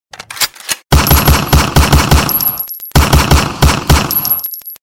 Пистолетные выстрелы